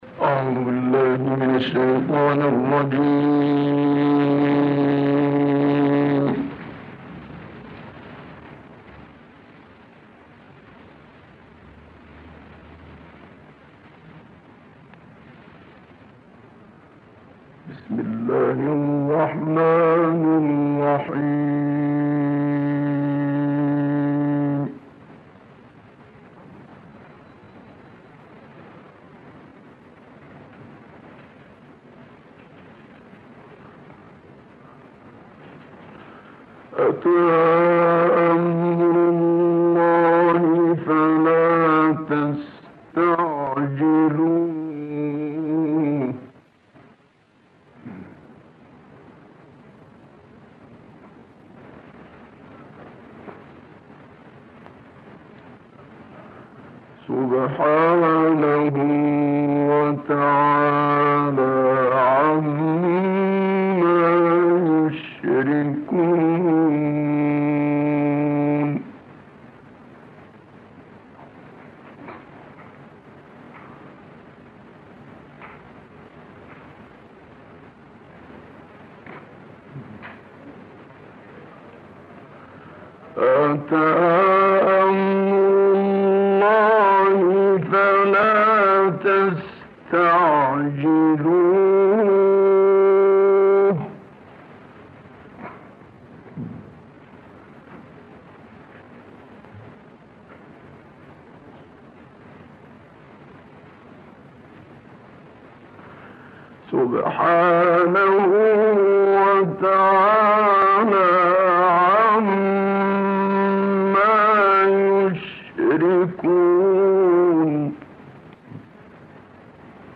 Minşevi’nin Irak’taki Kur’an tilaveti
Tahran, 19 Eylül 2016 - Dünyaca meşhur Kur’an okuyucusu Mısırlı Muhammed Sıddık Minşevi’nin 1965 yılında Irak’ta Nahl Suresi’nden birkaç ayeti tilavet ettiği ses kaydını okurlarımıza sunuyoruz.
Bu ses kaydı Üstad Minşevi’nin 1965 yılında Irak’ta tilavet ettiği Nahl Suresi’nin 1-23 ayetlerinin tilavetini içermektedir.